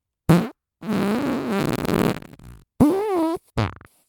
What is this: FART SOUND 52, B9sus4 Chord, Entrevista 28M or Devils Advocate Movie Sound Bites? FART SOUND 52